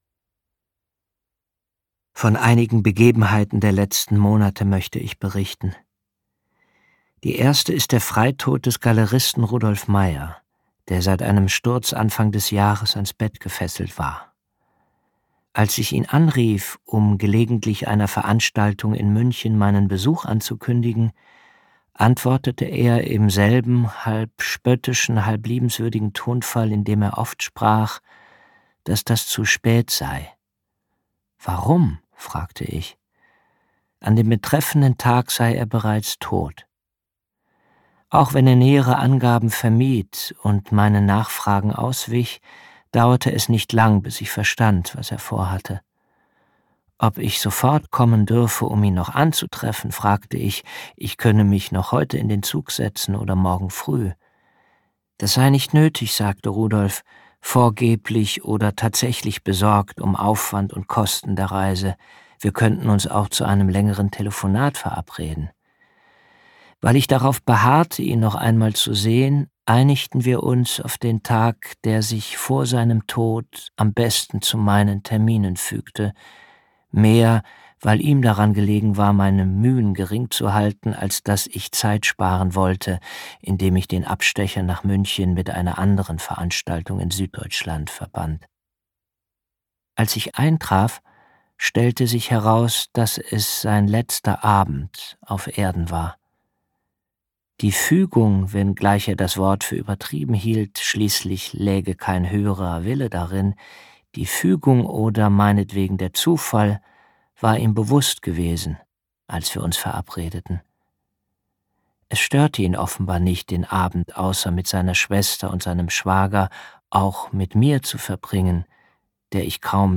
Ein existenzieller, hellsichtiger Roman unserer Zeit, gelesen von Jens Harzer.
• Sprecher:innen: Jens Harzer